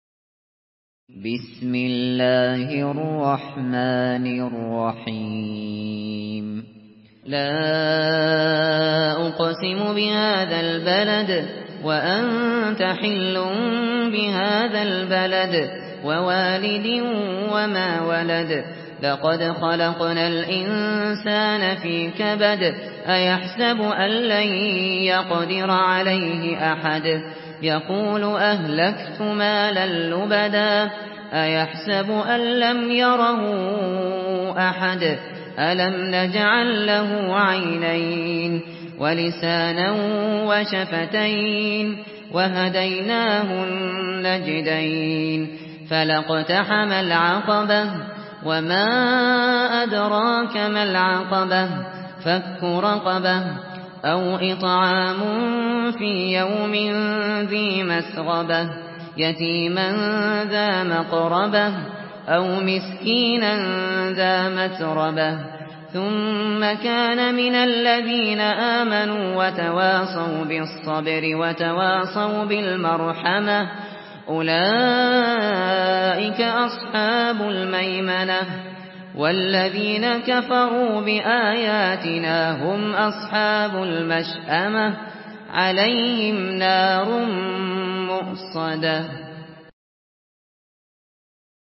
Surah البلد MP3 in the Voice of أبو بكر الشاطري in حفص Narration
Surah البلد MP3 by أبو بكر الشاطري in حفص عن عاصم narration. Listen and download the full recitation in MP3 format via direct and fast links in multiple qualities to your mobile phone.